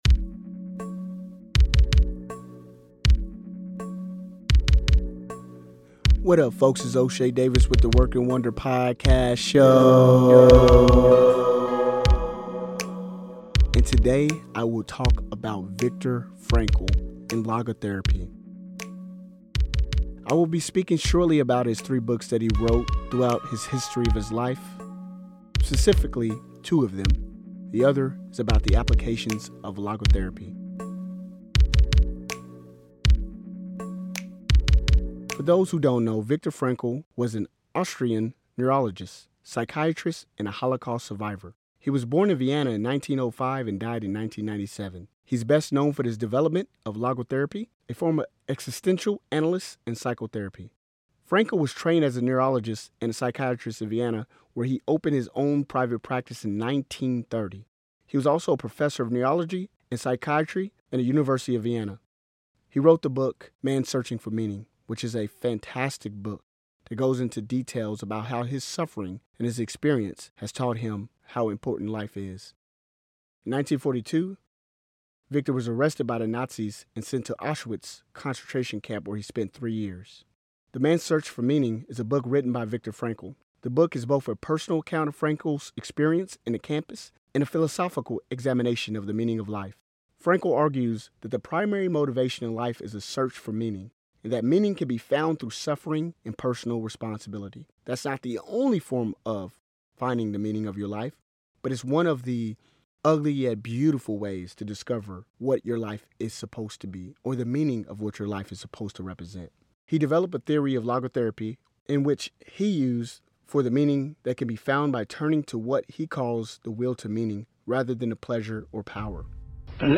(I do not own rights to the vocal clips of Viktor Frankl) Share Facebook X Subscribe Next Deputy Mayor Cassia Carr: Public Service, Law School, the value of support systems and building relationships.